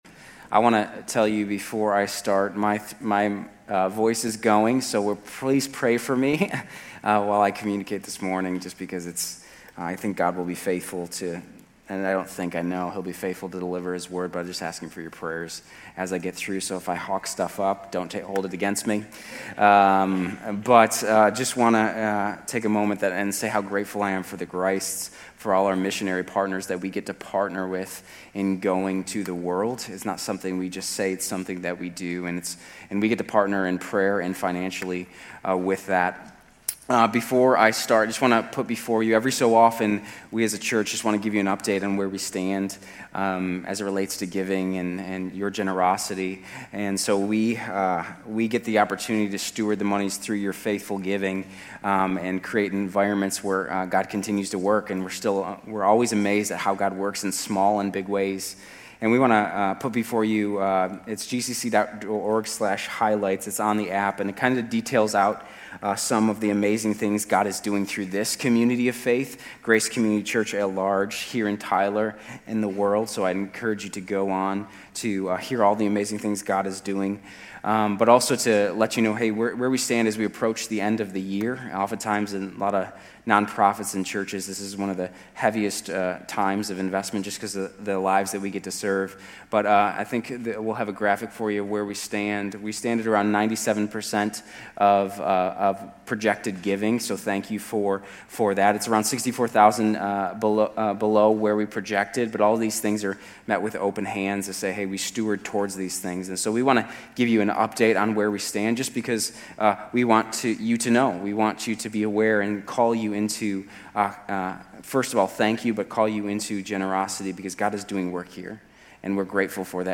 Grace Community Church University Blvd Campus Sermons 11_2 University Blvd Campus Nov 03 2025 | 00:36:32 1x 00:00 / 00:36:32 Subscribe Share RSS Feed Share Link Embed